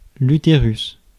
Ääntäminen
IPA : /ˈjuː.təɹ.əs/